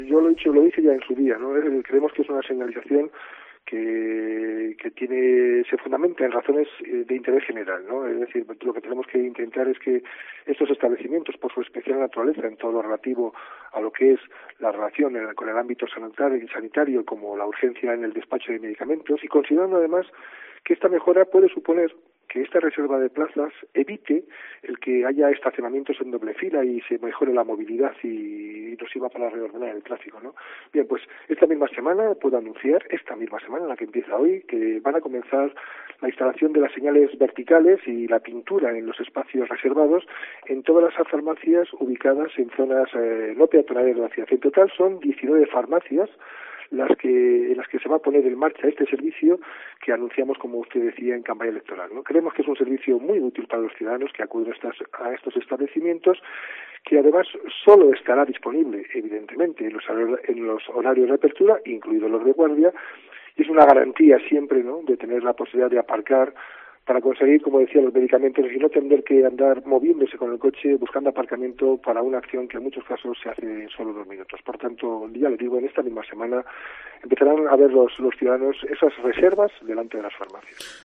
José Mazarías, alcalde de Segovia
El alcalde ha puntualizado en los micrófonos de COPE cuándo regirá el uso de estas plazas especiales que se van a crear.